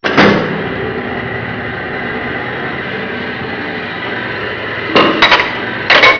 Submarine stuff: